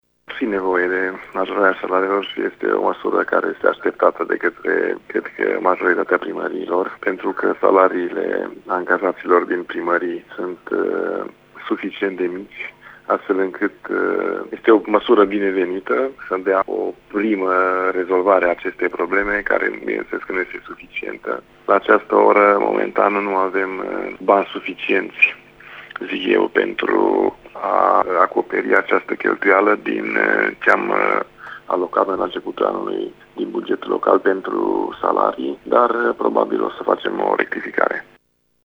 Primarul oraşului Luduş, Cristian Moldovan, spune că prevderea este un pas înainte pentru rezolvarea problemelor salariaţilor din administraţia locală: